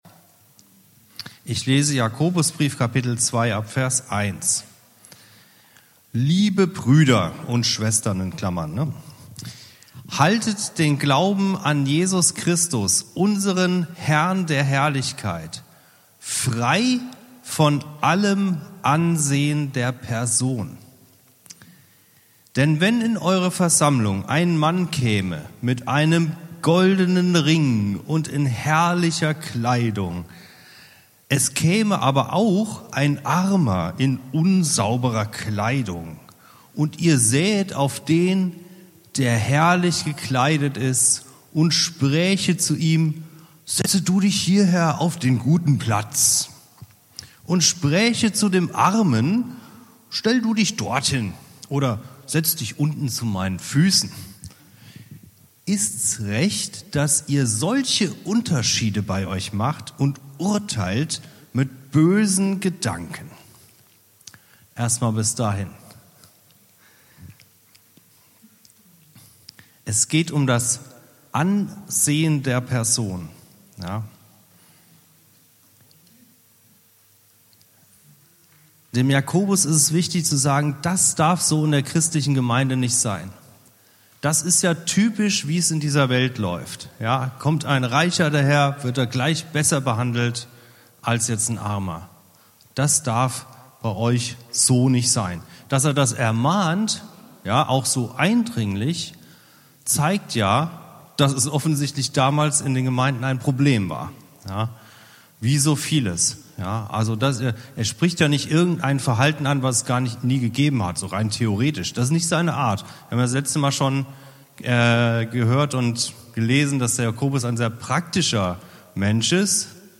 Bibelseminar